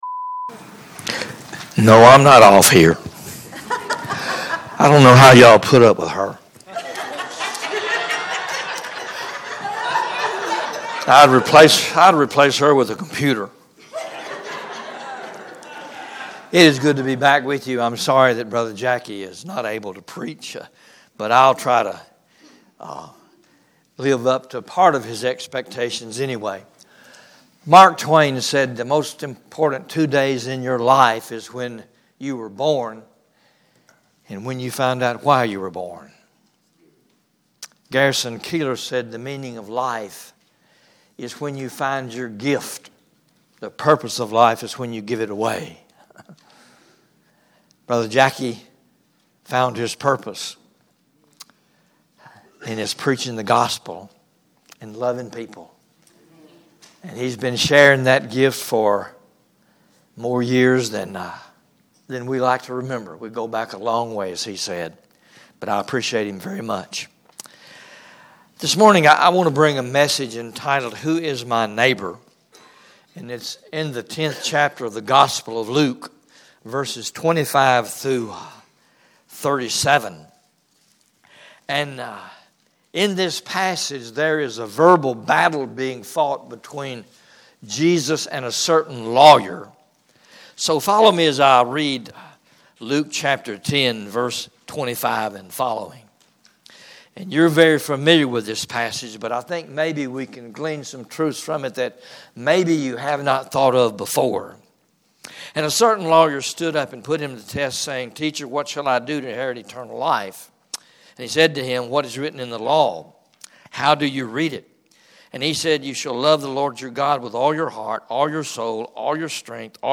Sermons - Calvary Baptist Church